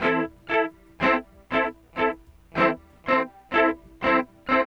62 GUIT 1 -L.wav